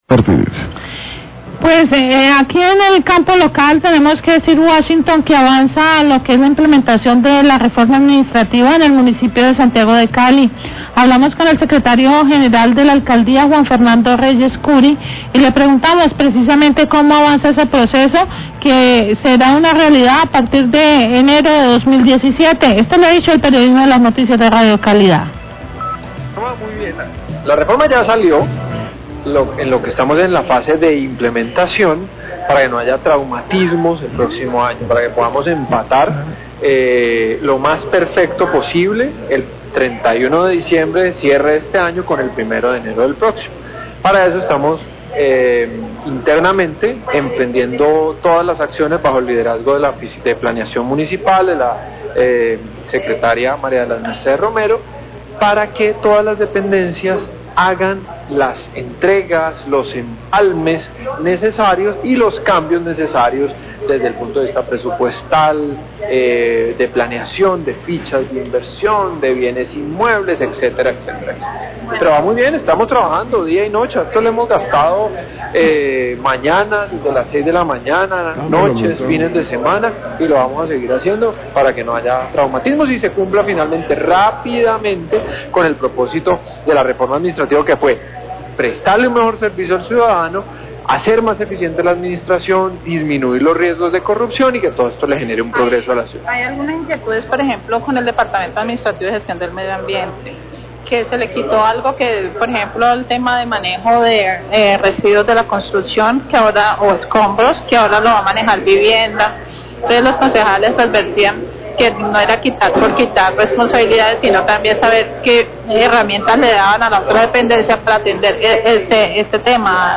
En entrevista, el secretario general de la Alcaldía, Juan Fernando Reyes, habló sobre el avance del proceso de la reforma administrativa en Cali, la cual se implementará a partir de enero de 2017.